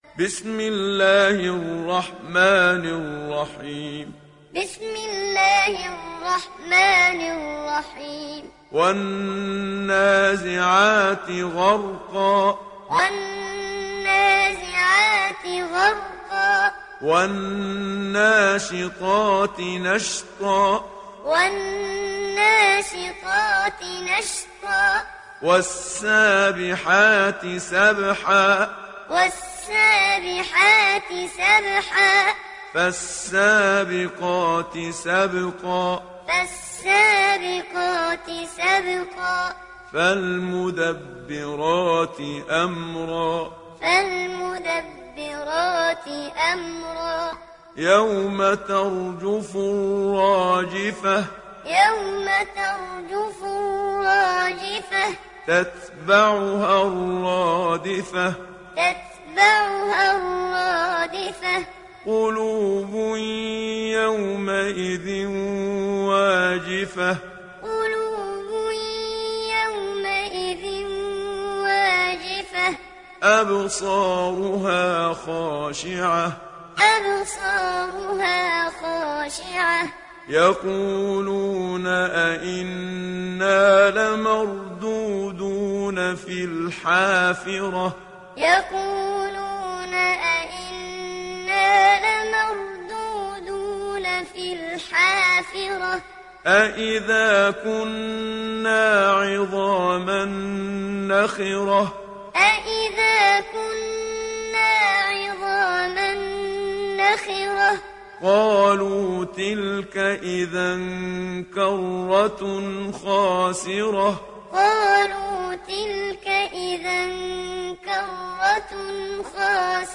تحميل سورة النازعات mp3 بصوت محمد صديق المنشاوي معلم برواية حفص عن عاصم, تحميل استماع القرآن الكريم على الجوال mp3 كاملا بروابط مباشرة وسريعة
تحميل سورة النازعات محمد صديق المنشاوي معلم